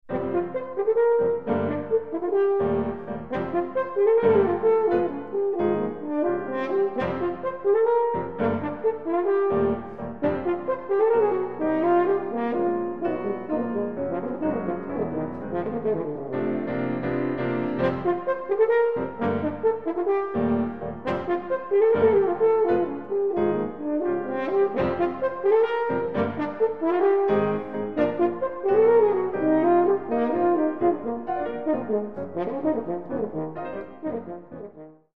Horn
Piano
Iwaki Auditorium, ABC Southbank, Melbourne